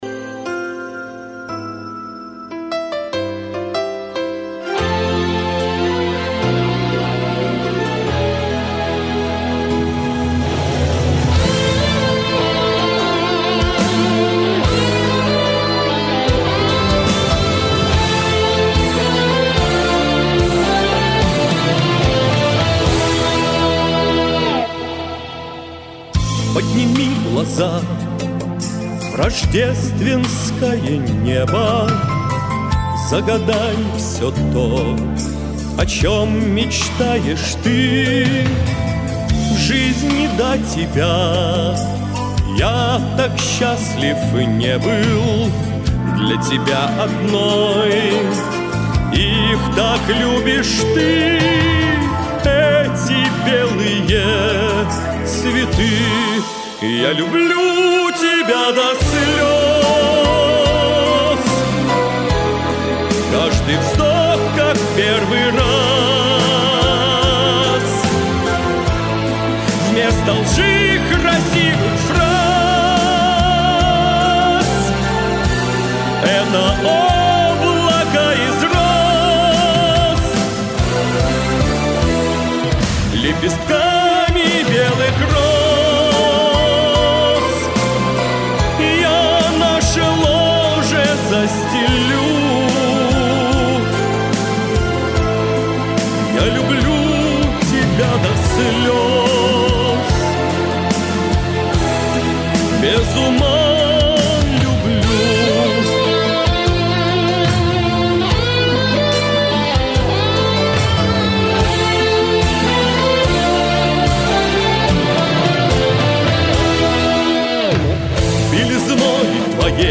Красиво спели! klass